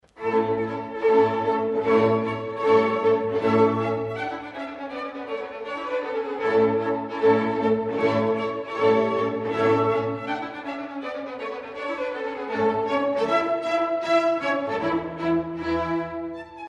Wir bieten Ihnen hier Hörproben aus Mitschnitten von Konzerten des Kieler Kammer Orchesters an, bitte klicken Sie auf den Link "Play".
W.A. Mozart, Divertimento in D-Dur KV 205 für Violine, Viola, Bass, Fagott, 2 Hörner, Mozart-Konzert 7.1.2002, Live Mitschnitt